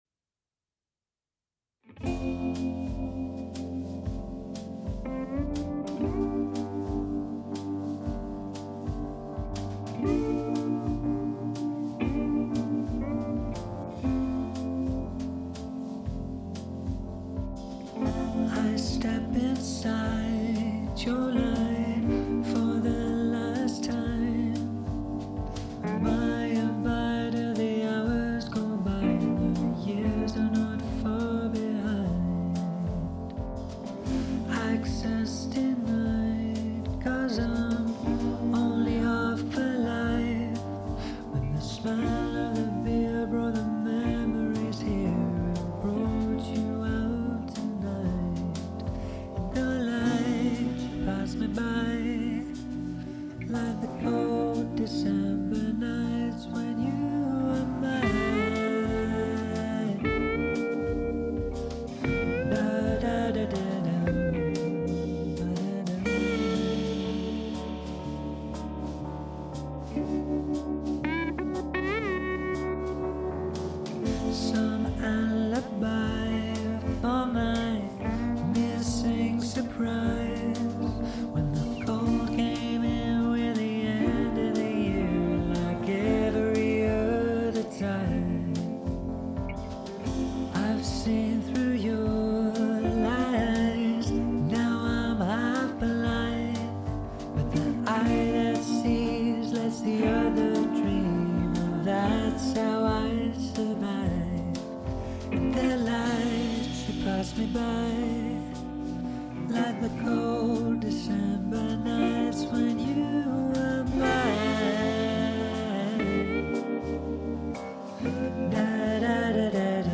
I did find the master a lot more squashy and unpleasant than the mix, but my mix was quiet and needed a lot of boosting (I used mostly the Fab Filter L-2 for this).